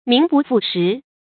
注音：ㄇㄧㄥˊ ㄅㄨˋ ㄈㄨˋ ㄕㄧˊ
名不副實的讀法